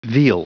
Prononciation du mot veal en anglais (fichier audio)
Prononciation du mot : veal